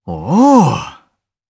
Kibera-Vox_Happy4_jp.wav